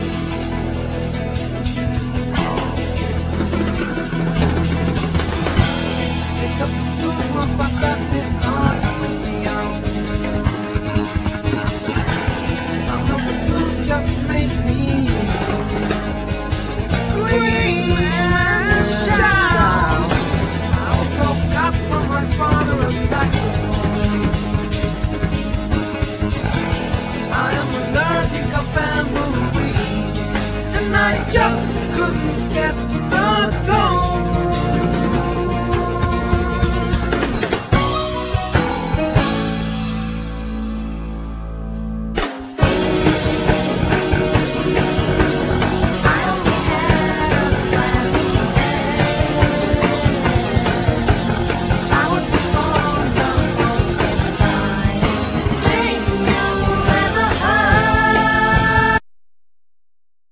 Vocal,Synthsizer,Harmonica
Drums,Keyboards
Guitar,Mandlin
Bass,Melodica
Cello,Bass